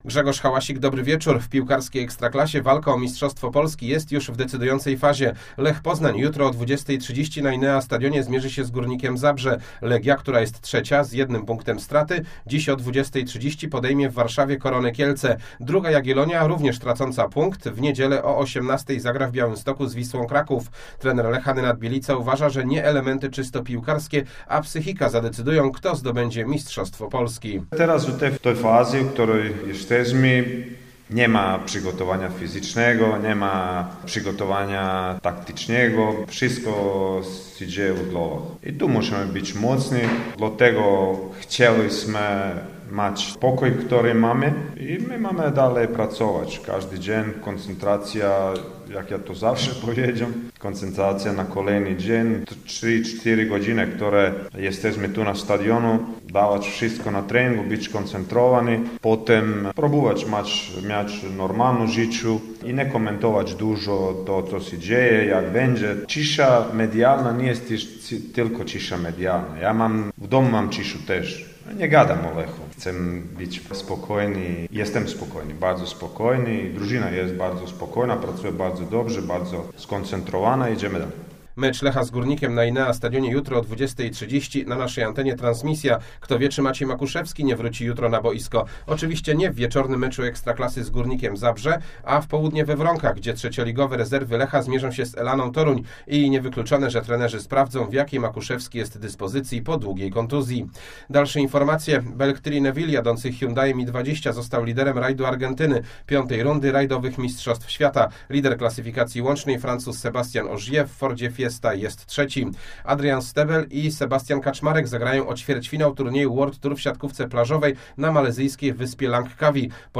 27.04 serwis sportowy godz. 19:05